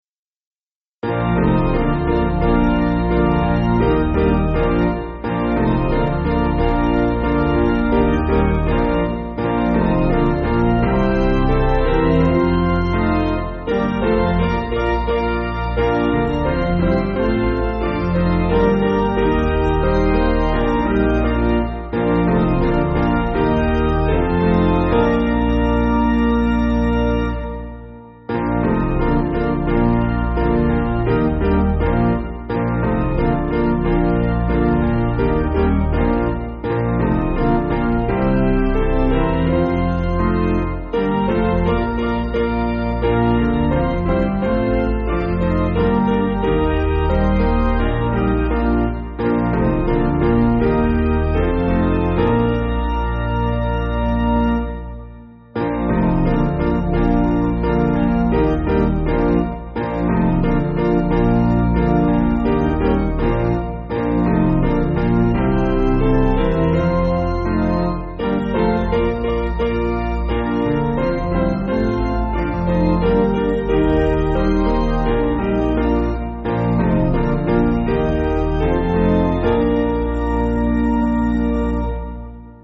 Basic Piano & Organ
(CM)   4/Bb